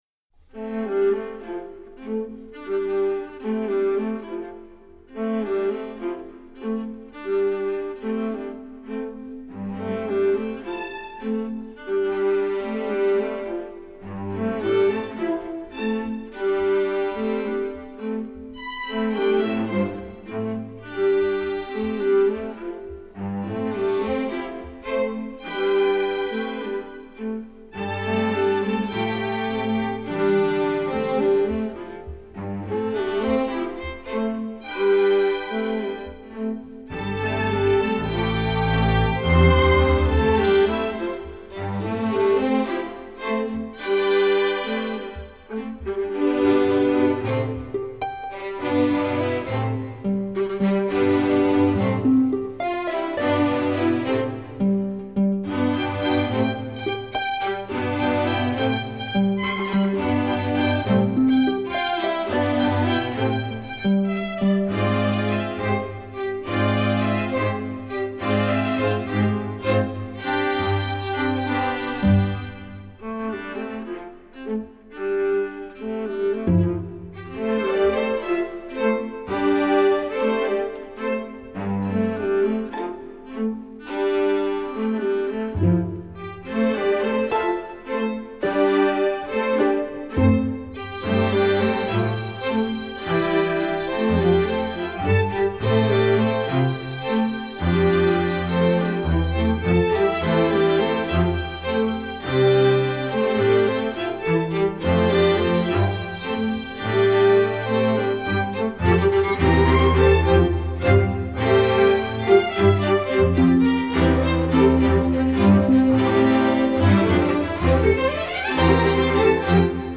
Instrumentation: string orchestra
String bass part:
Harp part: